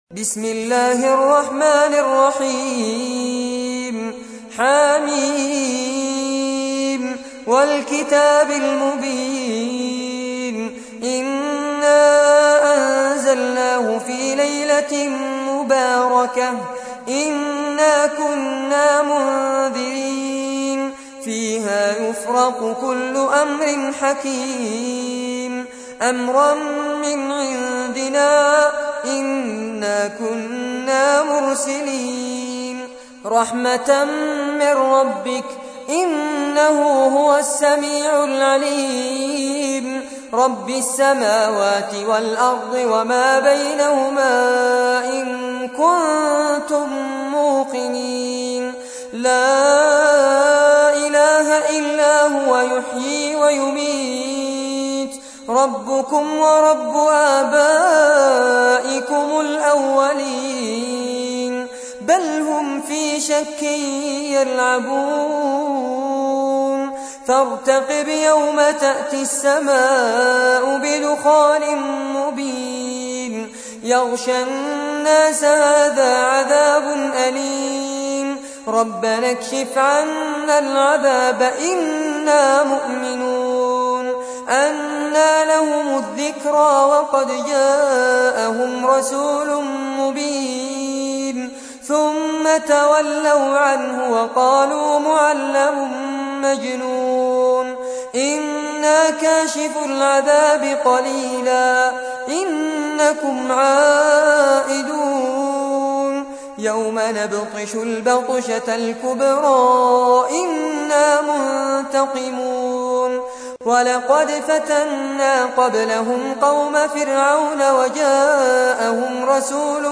تحميل : 44. سورة الدخان / القارئ فارس عباد / القرآن الكريم / موقع يا حسين